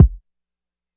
SY_Kick.wav